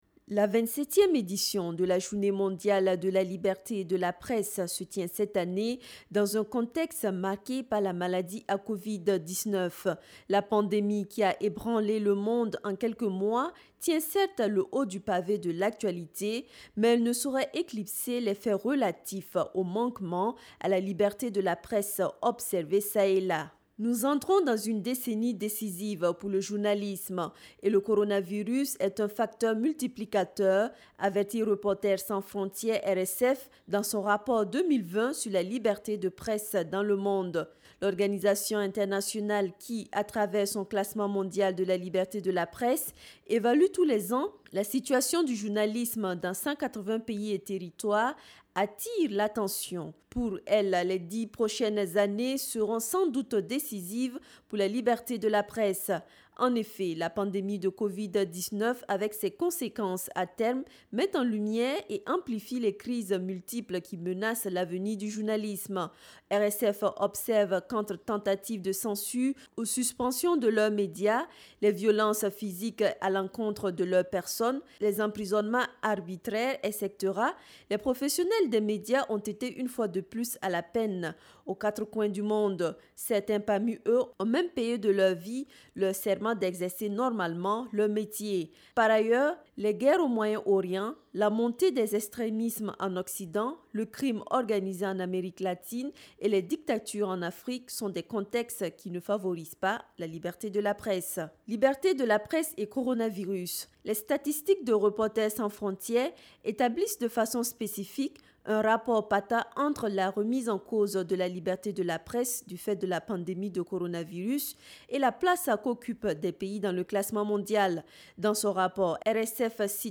Voix off liberté de la presse.mp3 (7.69 Mo)